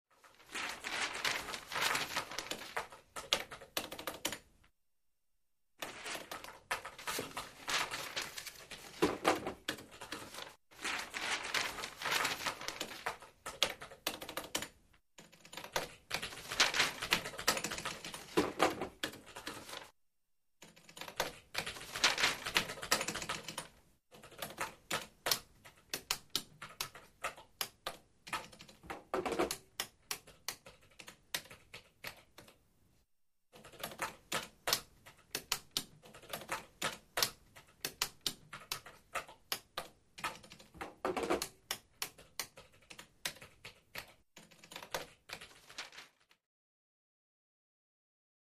Office Background: Typing And Paper Movement.